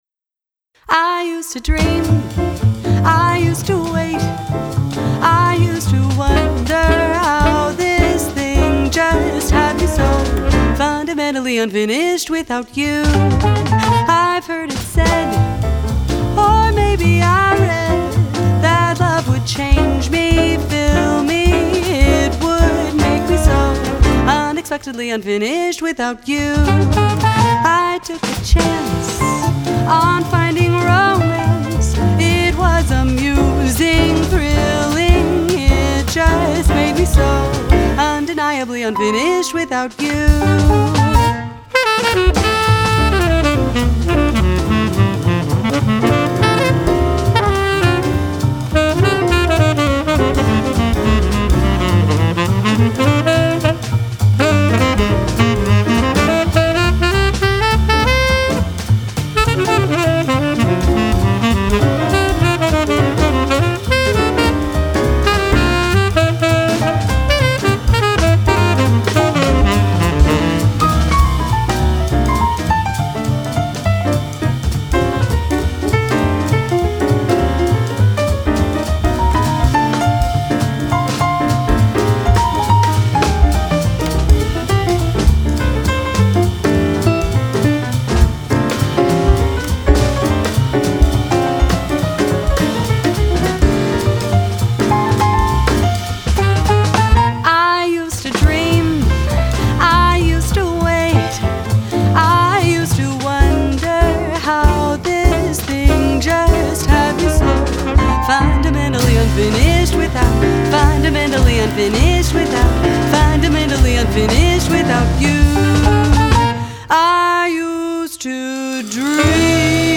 FILE: Jazz Vox